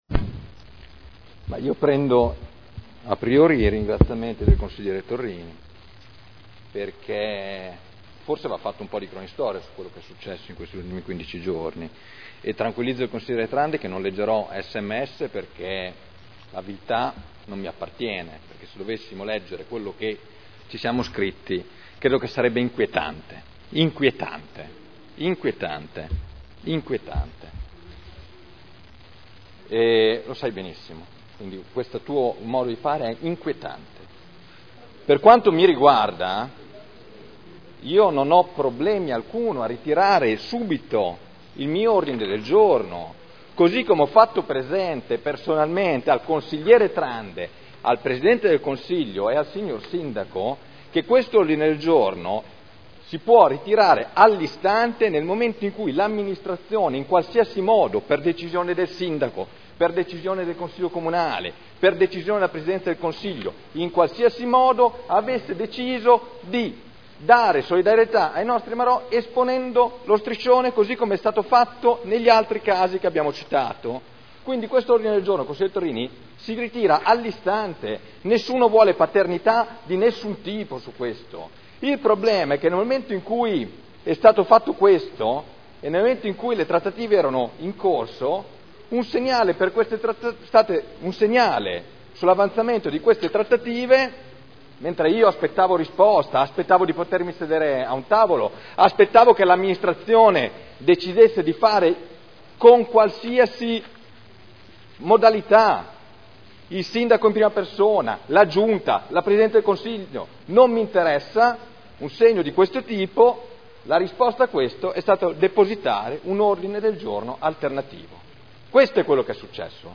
Dibattito